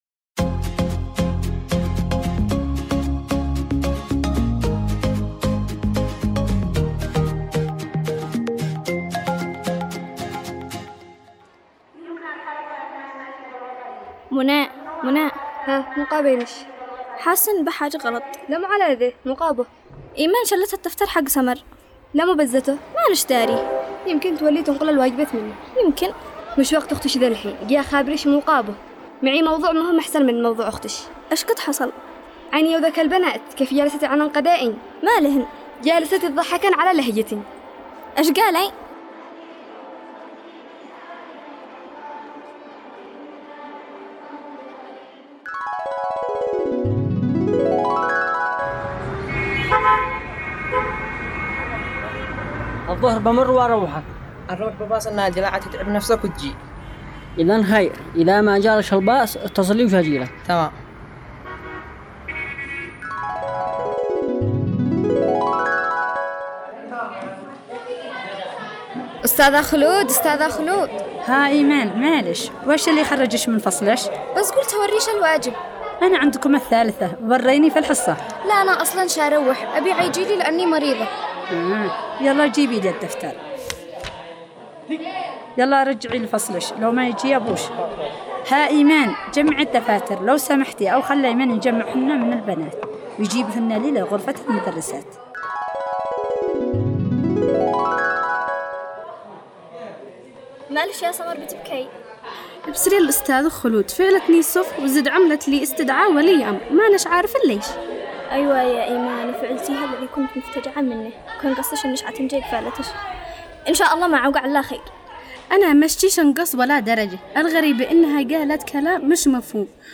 دراما رمضانية - عائلة مصطفى